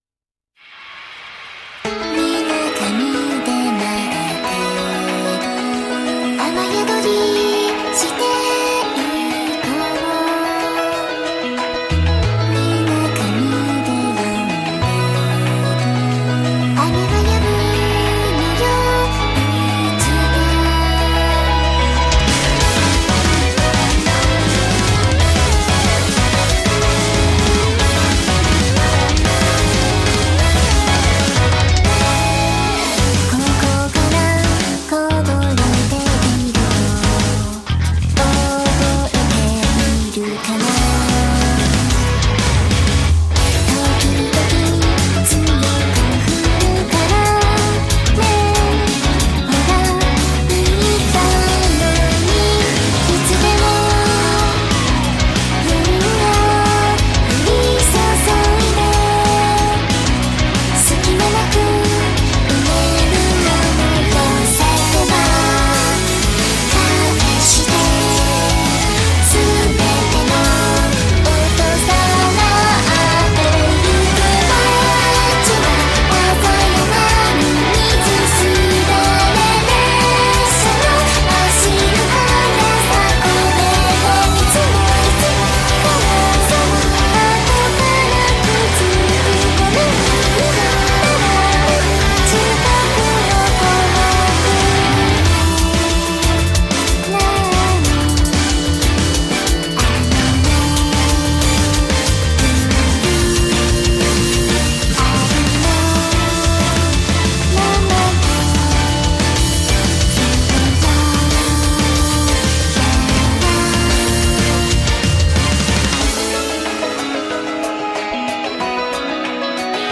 BPM185
Audio QualityPerfect (Low Quality)